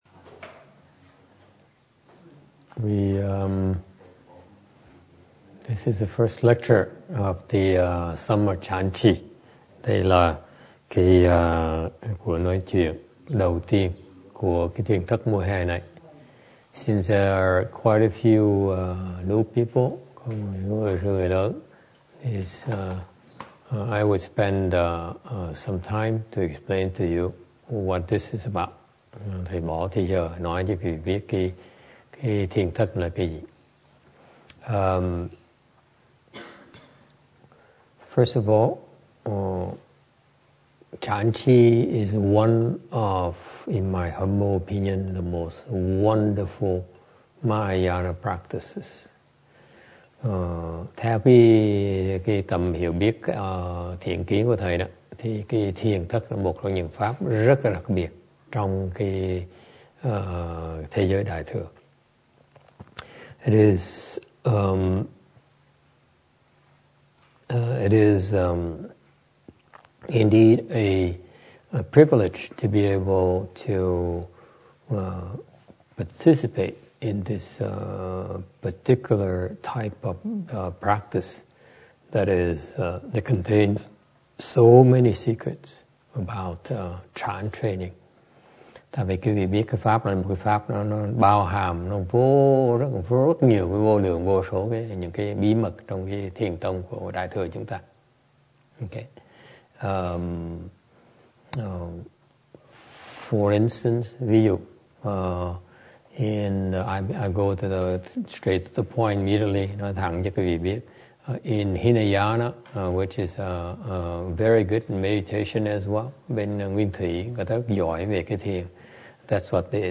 법문